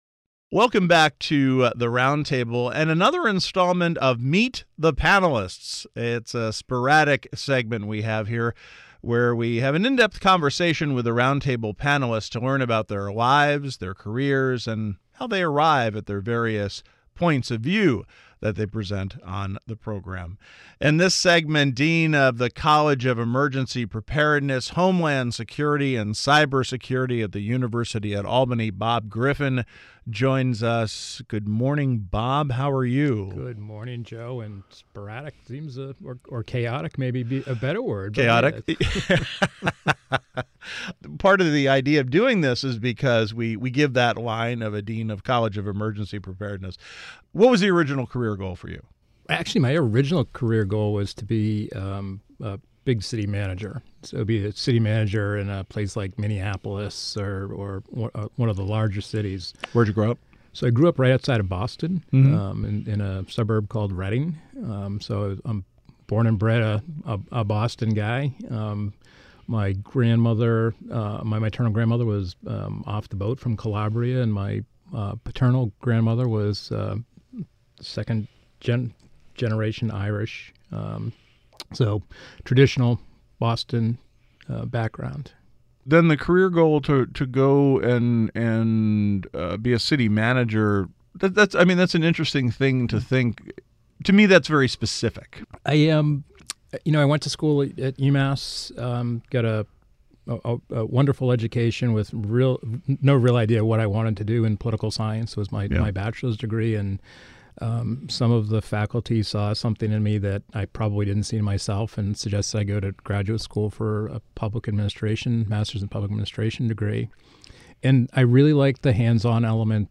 "Meet the Panelist" is a sporadic segment where we have in depth discussions with Roundtable Panelists to learn about their lives, careers and how they arrive at their various points of view.